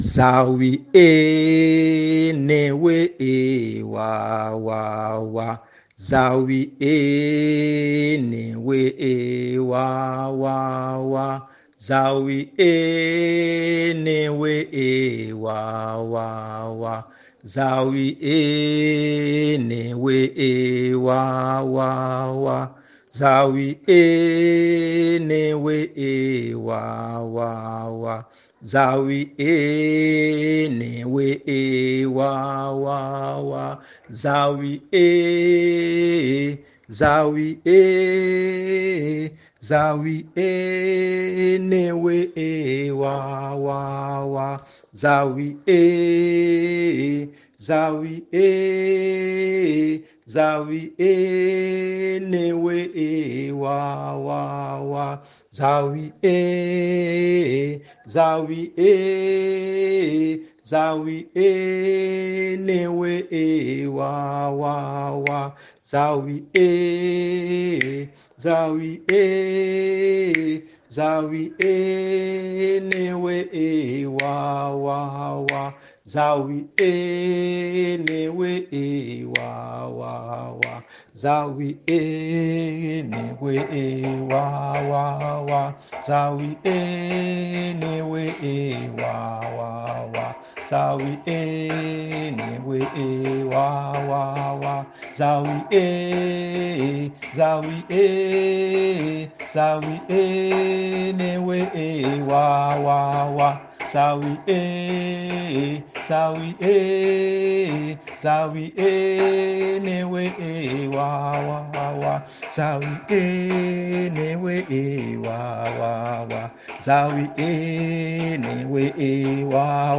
Tamburi (Djembé), Danze, Canzoni, cultura dall'Africa
ritmo e ballo